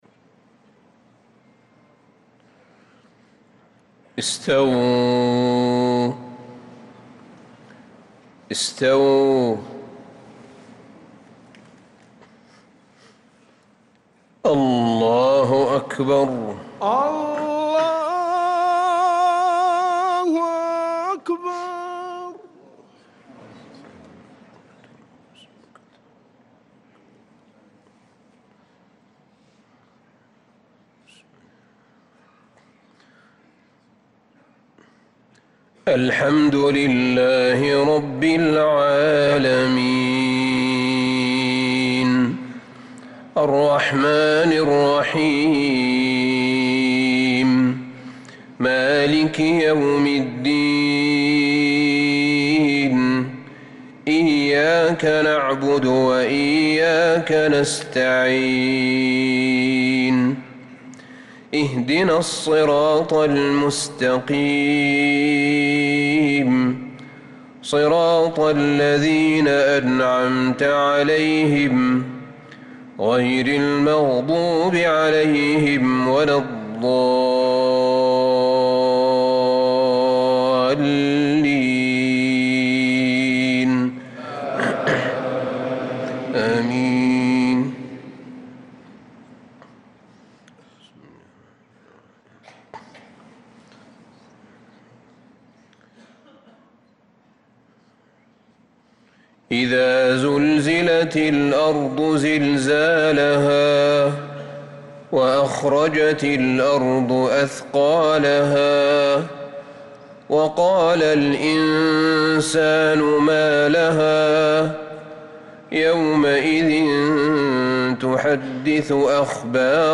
صلاة المغرب للقارئ أحمد بن طالب حميد 15 شوال 1445 هـ
تِلَاوَات الْحَرَمَيْن .